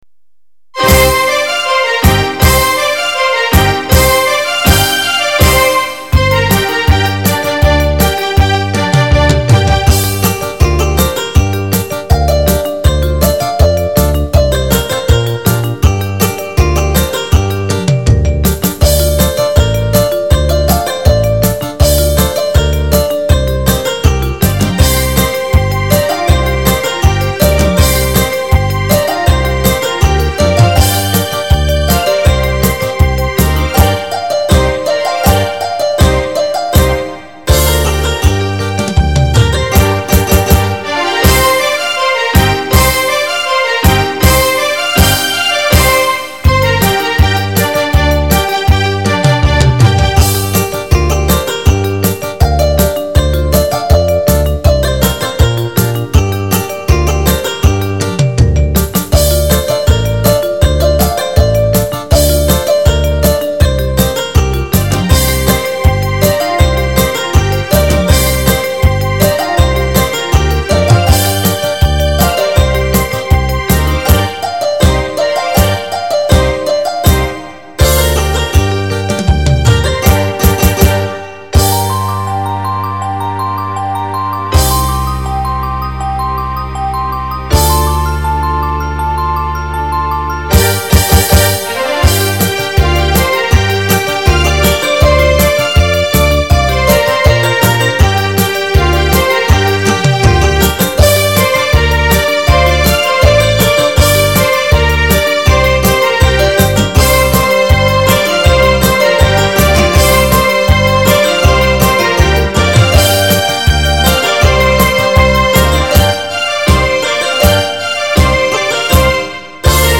无 调式 : 降B 曲类